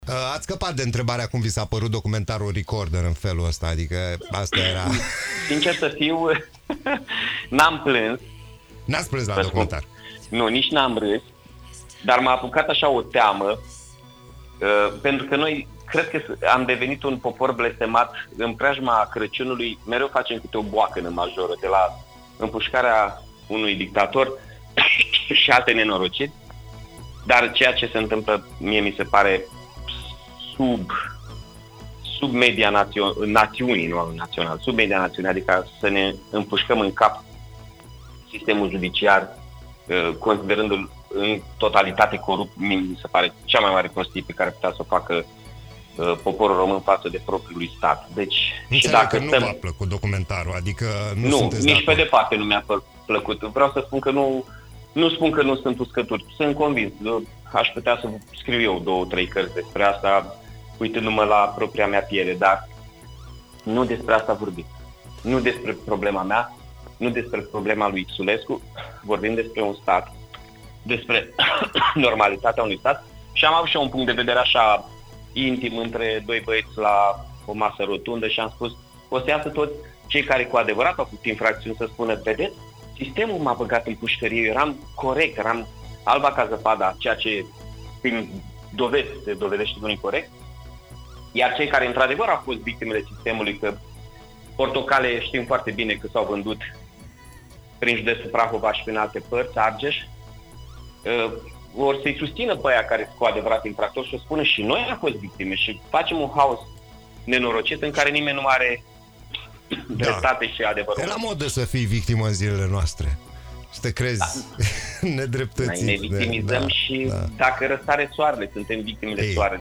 Invitat în emisiunea Play The Day pentru a dezbate probleme actuale din administrația publică, șeful municipalității Iași, Mihai Chirica, a fost întrebat dacă a vizionat documentarul Recorder „Justiție capturată”, dezvăluiri care au scos România în stradă pentru o justiție curată, și cum îl comentează.